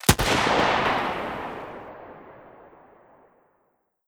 Sniper1_Shoot 03.wav